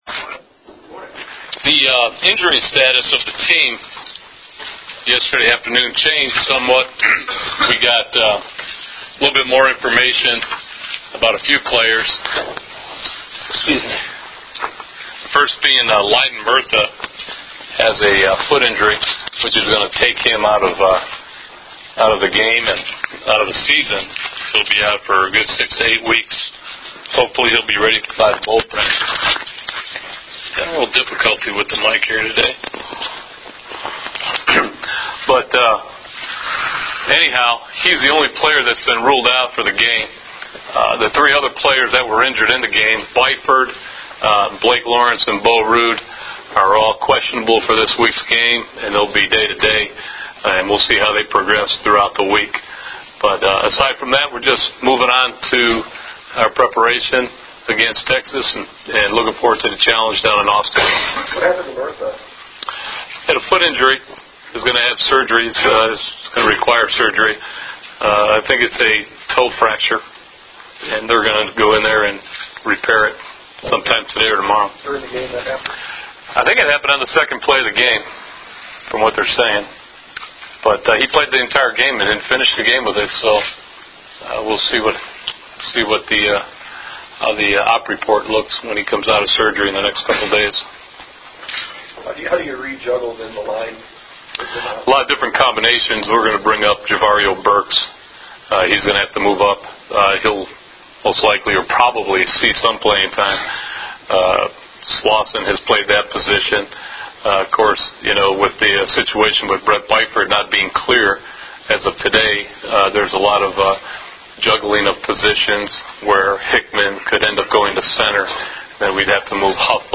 Nebraska Coach Bill Callahan?welcomed the media to Memorial Stadium on Tuesday?for the Huskers'?weekly press conference to discuss?NU's?upcoming?battle with Texas.